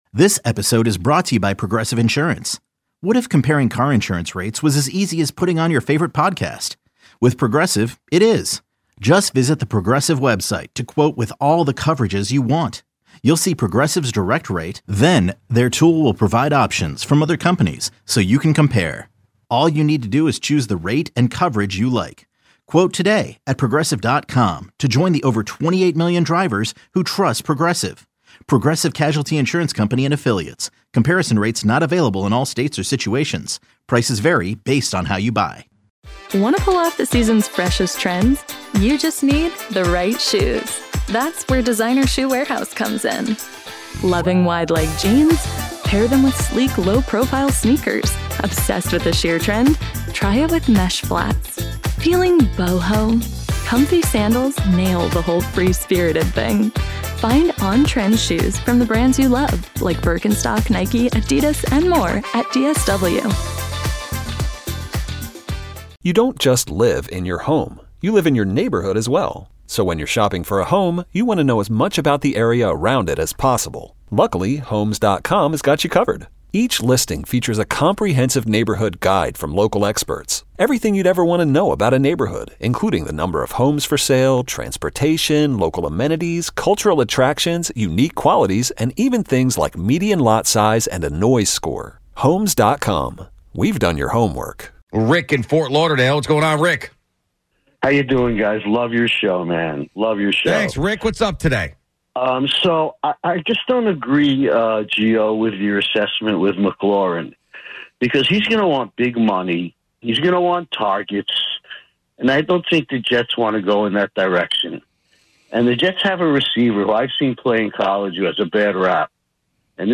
Callers On McLaurin & Giants Outlook